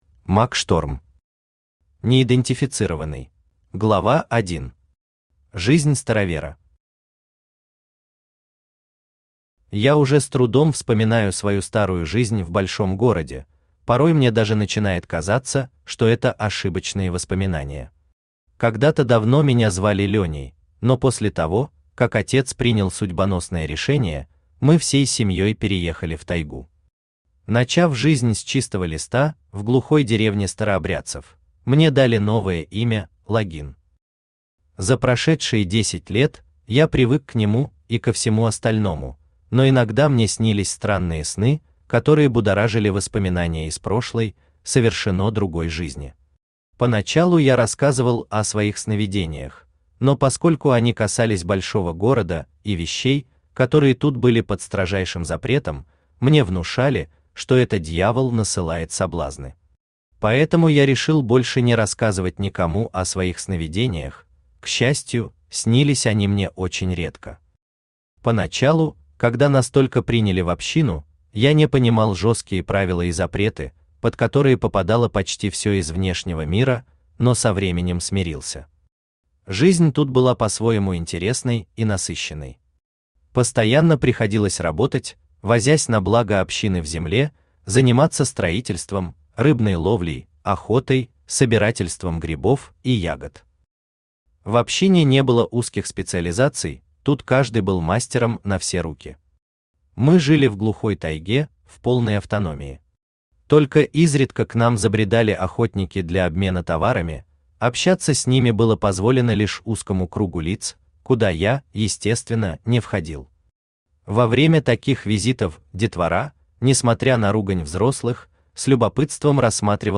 Аудиокнига Неидентифицированный | Библиотека аудиокниг
Aудиокнига Неидентифицированный Автор Мак Шторм Читает аудиокнигу Авточтец ЛитРес.